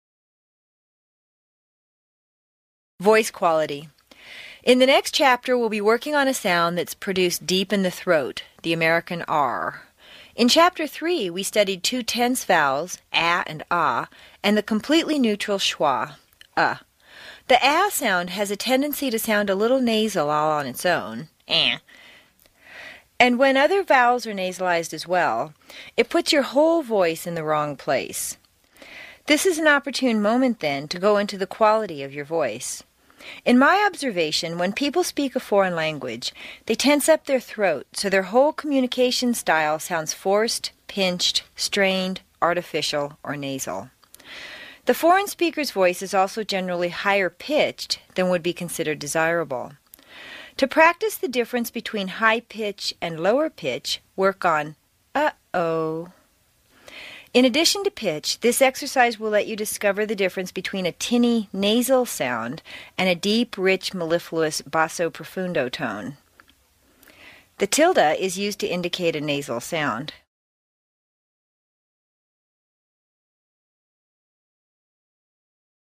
美式英语正音训练第81期:音质 听力文件下载—在线英语听力室
在线英语听力室美式英语正音训练第81期:音质的听力文件下载,详细解析美式语音语调，讲解美式发音的阶梯性语调训练方法，全方位了解美式发音的技巧与方法，练就一口纯正的美式发音！